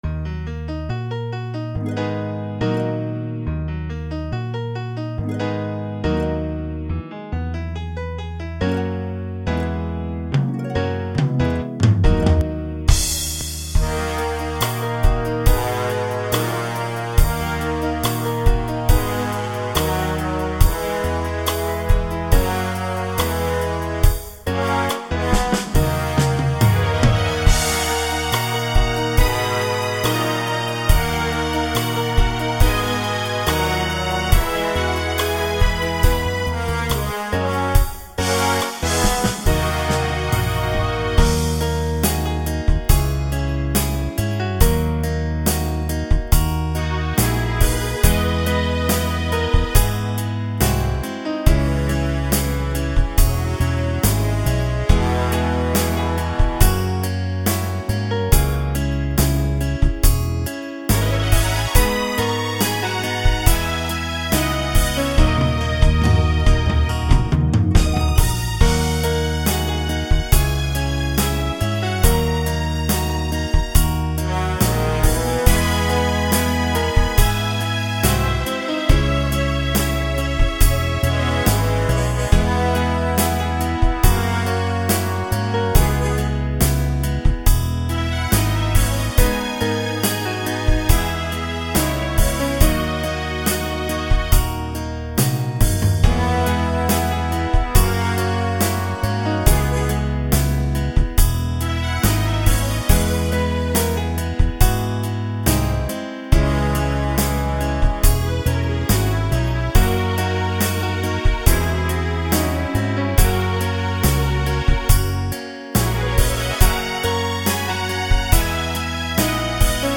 صورت-مهتابی-بی-کلام.mp3